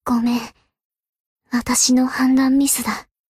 贡献 ） 分类:蔚蓝档案语音 协议:Copyright 您不可以覆盖此文件。
BA_V_Shiroko_Tactic_Defeat_1.ogg